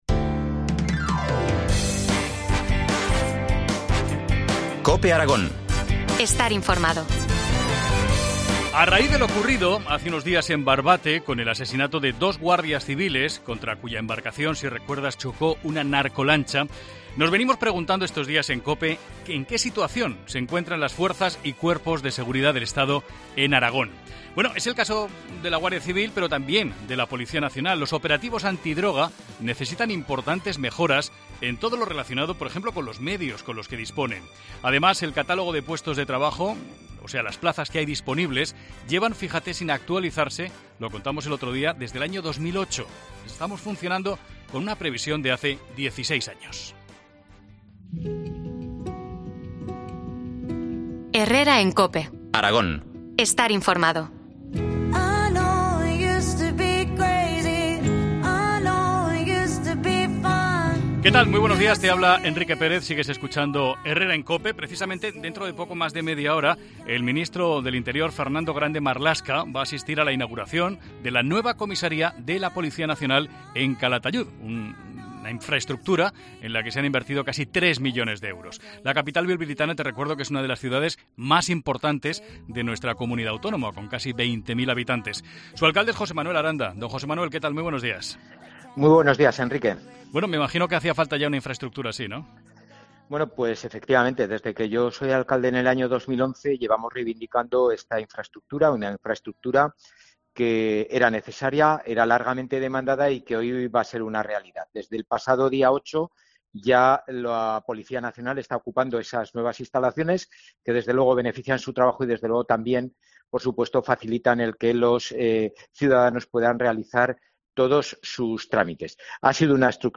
El alcalde de Calatayud, Jose Manuel Aranda, explica en COPE los detalles de esta infraestructura tan demandada en la capital bilbilitana que actualmente cuenta con 66 agentes
Entrevista al alcalde de Calatayud, José Manuel Aranda, sobre la nueva comisaría de Policía Nacional